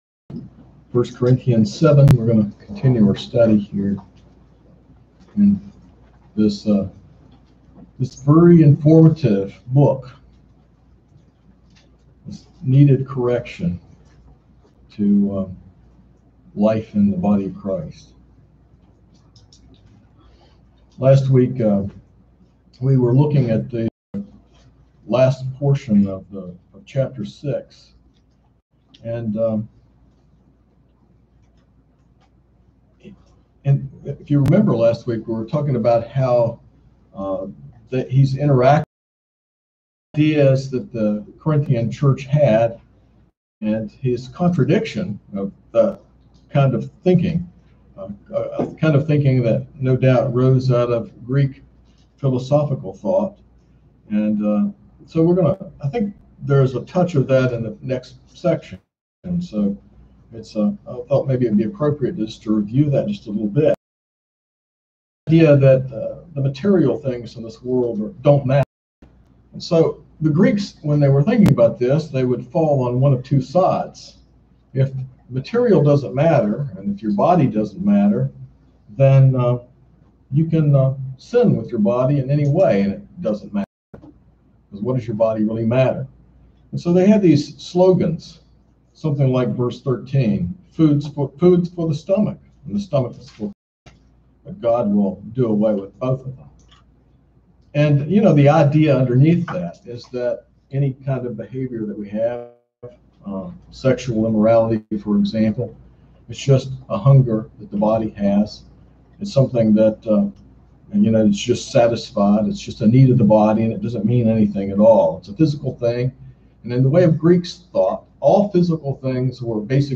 sermon-7-18-21.mp3